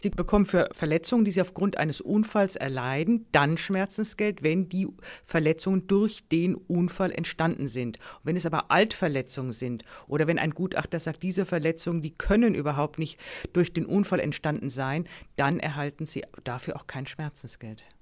O-Ton: Schmerzensgeld nur für Verletzungen durch einen Verkehrsunfall